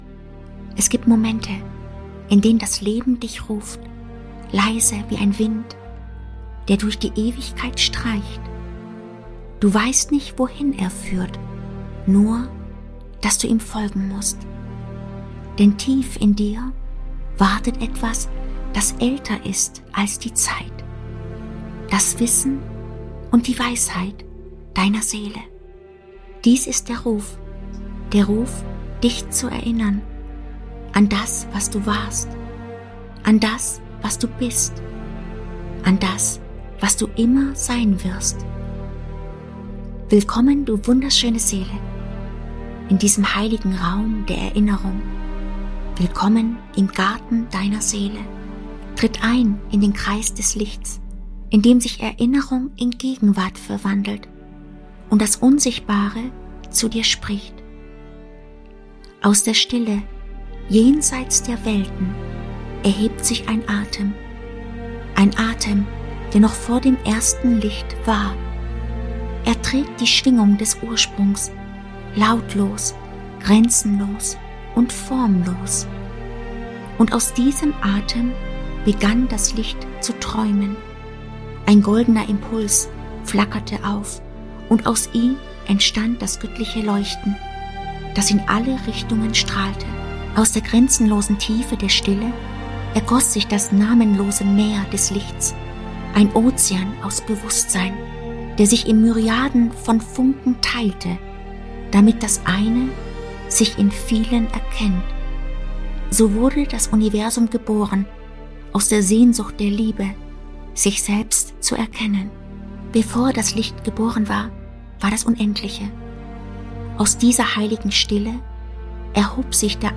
Geführte Meditation